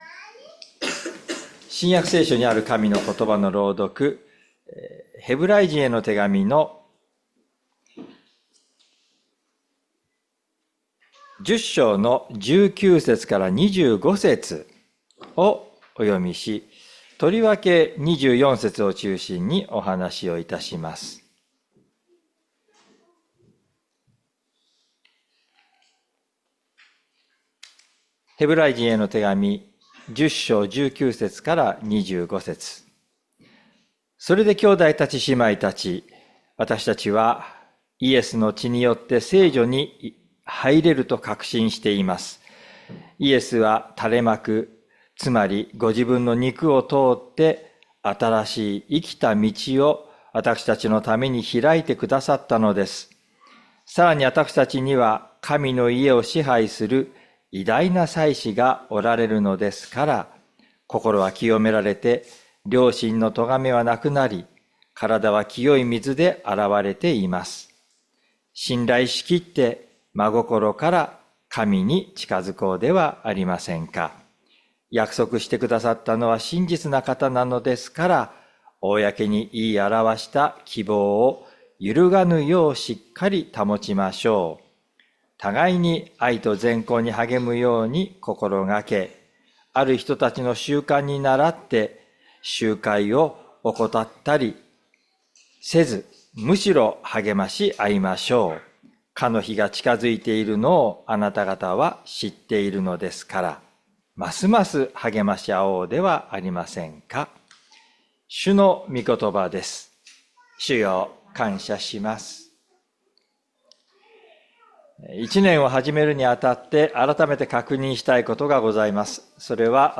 日曜朝の礼拝
礼拝説教を録音した音声ファイルを公開しています。